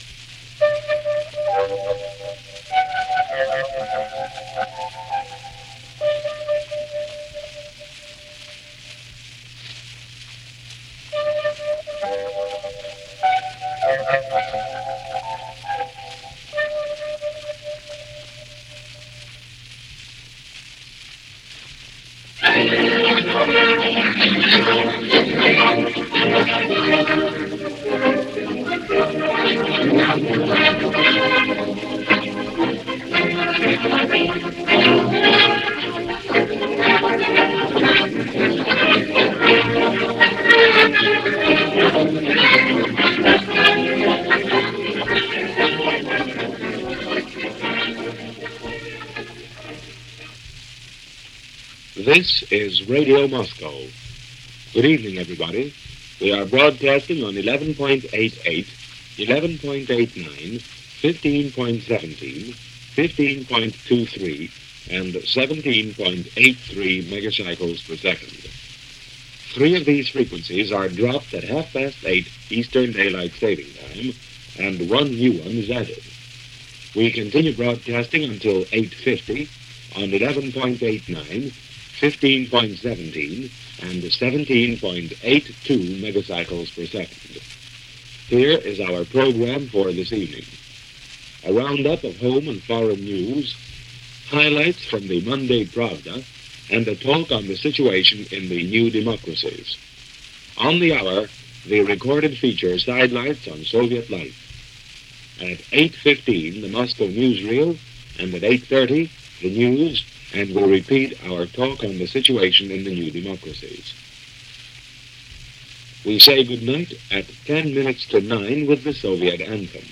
News for this day from Radio Moscow.
News-Radio-Moscow-July-7-1947.mp3